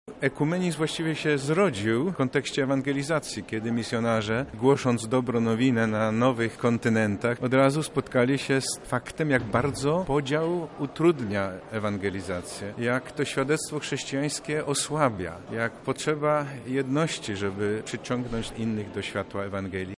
O ekumenizmie mówi Metropolita Lubelski, arcybiskup profesor Stanisław Budzik.